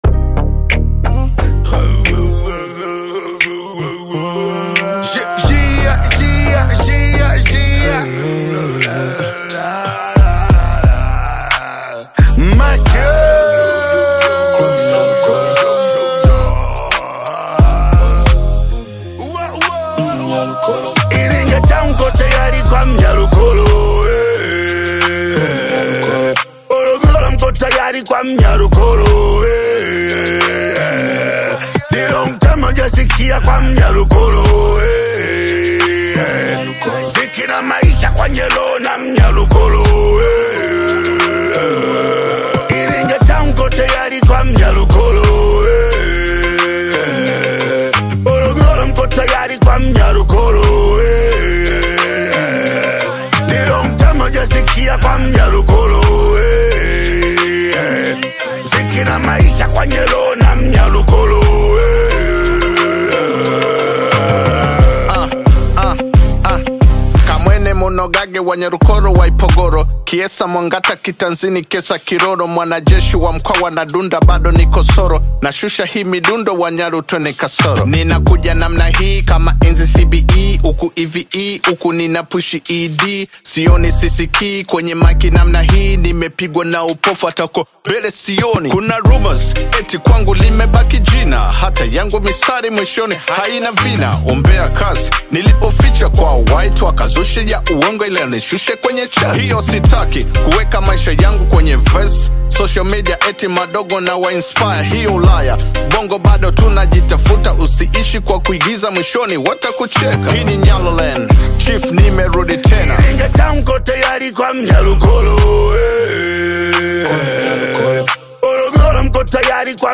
This audio release is a masterclass in Bongo-Trap Fusion:
creating a dynamic vocal contrast.